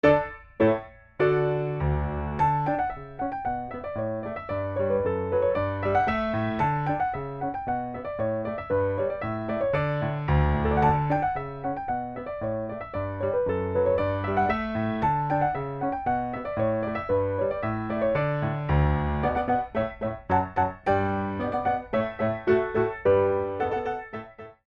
Original Music for Ballet Class
Recorded on a Steinway B at Soundscape
4 Count introduction included for all selections
4/4 - 64 with repeat